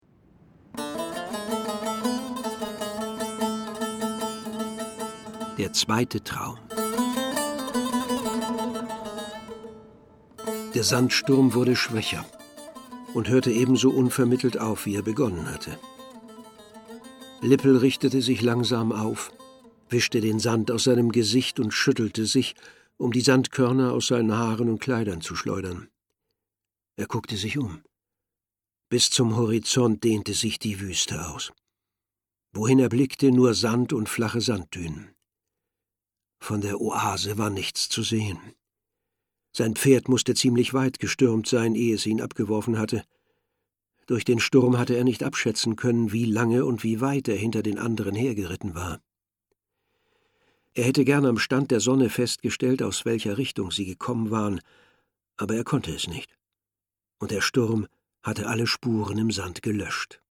Friedhelm Ptok (Sprecher)
Mit dem Preis 'Kinder- und Jugendhörbuch des Jahres 2003' zeichneten die Juroren der hr2-Hörbuch-Bestenliste die ungekürzte Lesung von Paul Maars Kinderbuch 'Lippels Traum' aus.